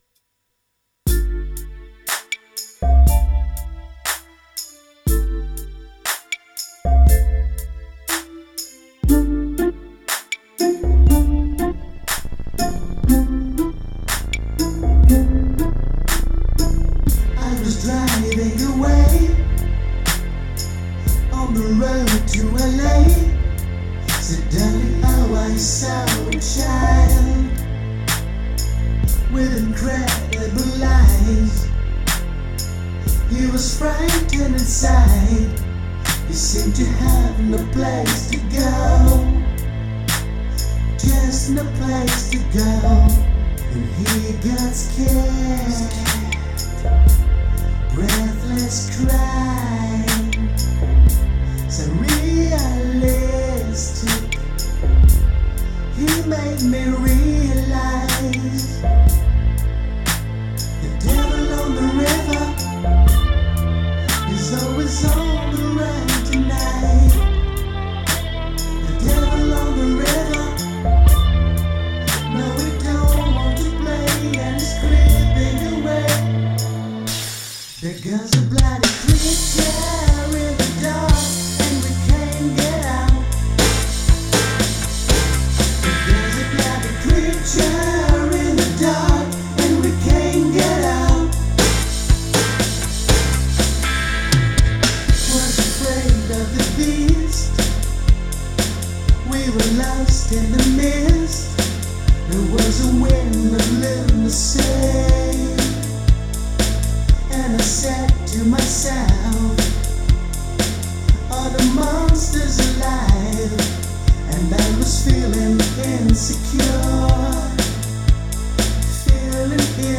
dans le plus pur style Brit Pop des années 1960 à 1980